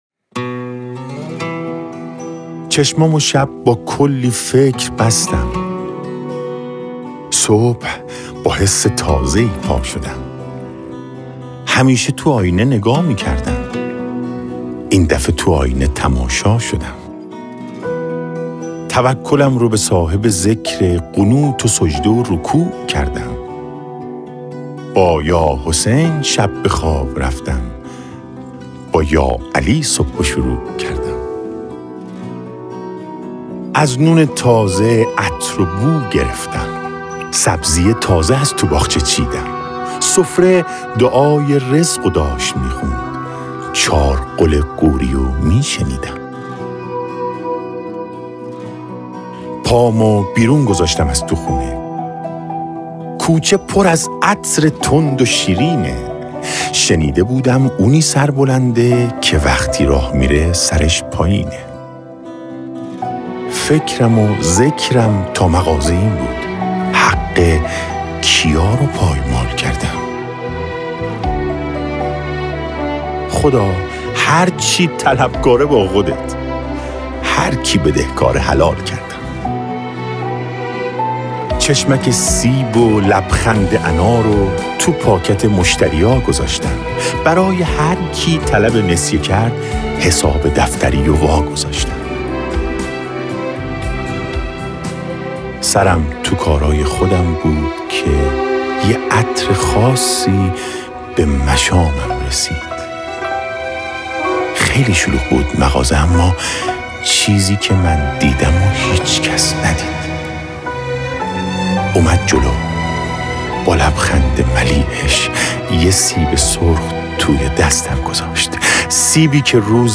نماهنگ مهدویت
نماهنگ زیبای میلاد امام زمان